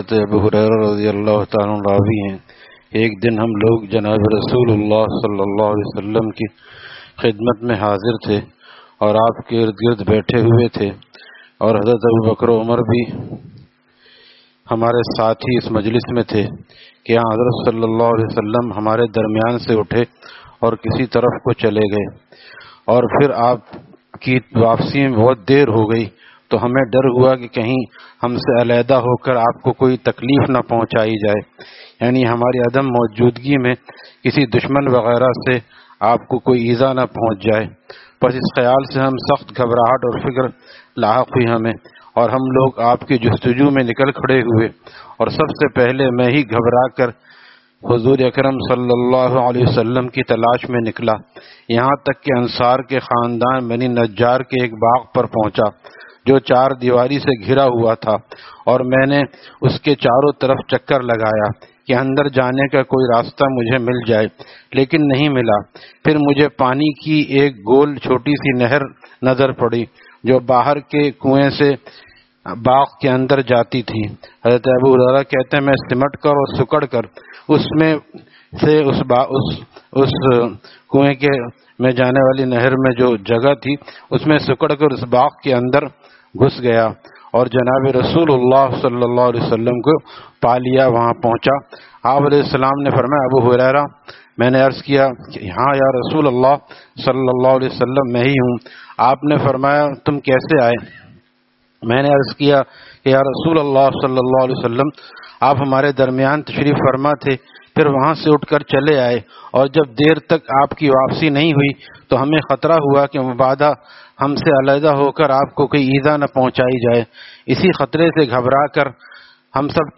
Taleem After Fajor at Jama Masjid Gulzar e Muhammadi, Khanqah Gulzar e Akhter, Sec 4D, Surjani Town